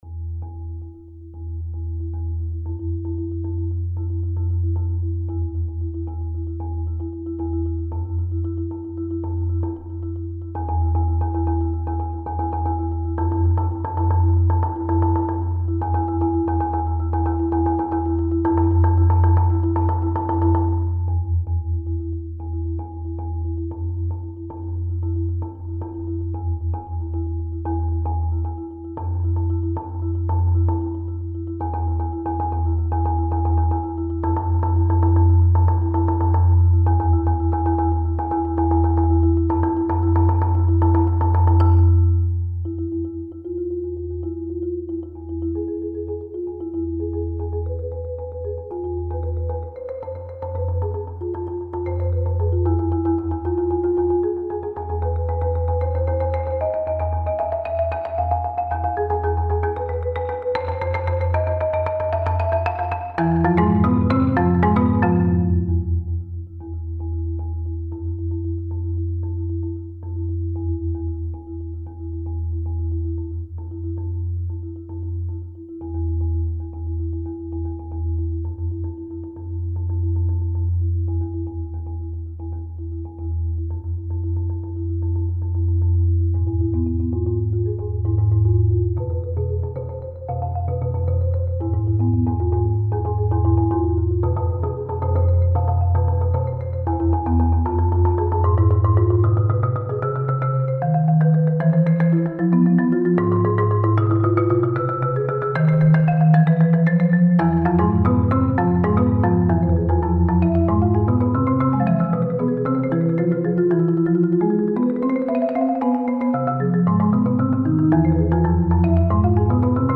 Genre: Duet for 2 Marimbas
Marimba 1 [4-octave]
Marimba 2 [4.6-octave]
*May be performed on one 4.6-octave instrument